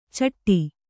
♪ caṭṭi